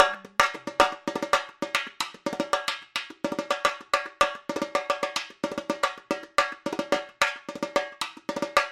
桑巴打击乐 " X Repinique 4 Bar A
描述：传统桑巴乐器的循环播放
Tag: 4 repinique